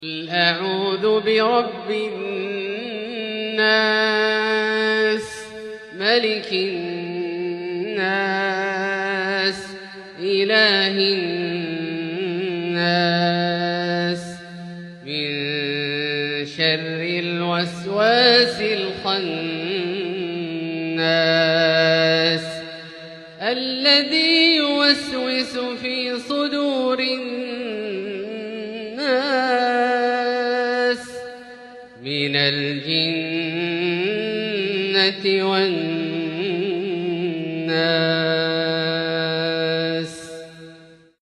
Beautiful Quran Recitation
Surah An Nas 114:1-6 🗓 Maghrib, Jun 20, 2023
📍 Masjid Al Haram, Makkah, Saudi Arabia